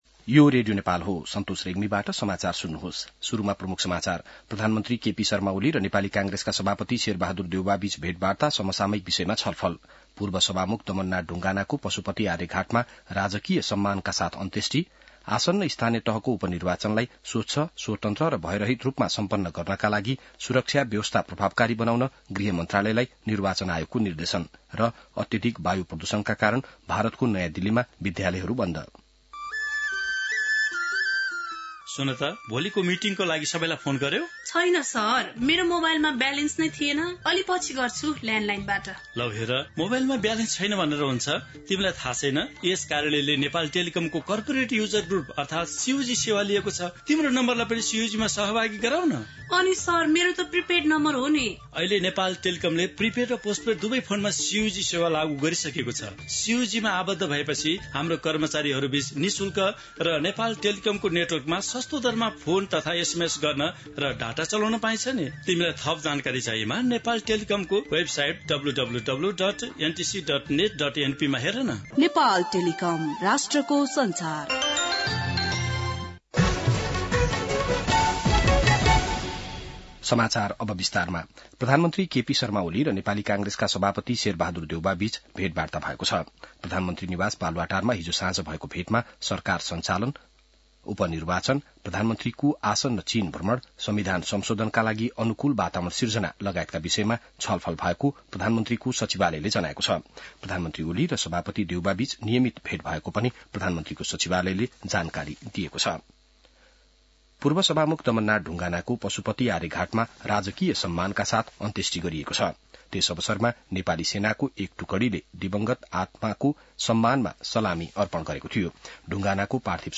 बिहान ७ बजेको नेपाली समाचार : ५ मंसिर , २०८१